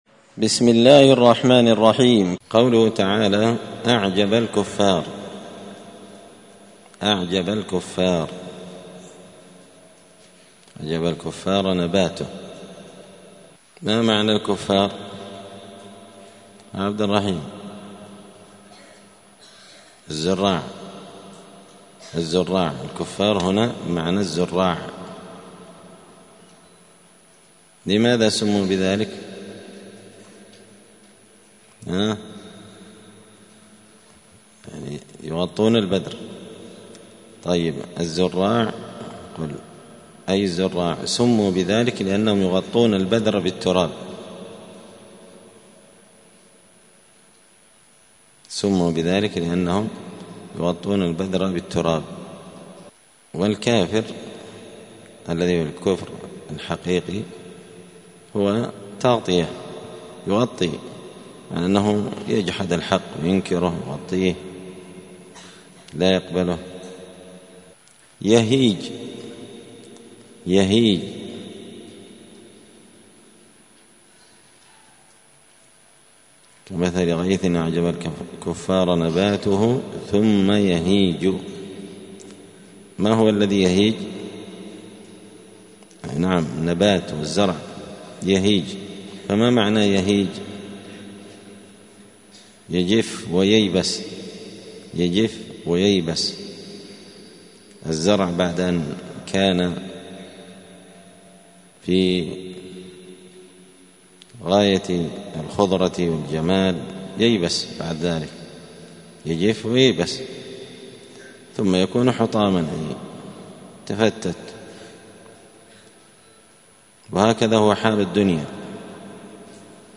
*(جزء الذاريات سورة الحديد الدرس 196)*